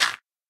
gravel1.ogg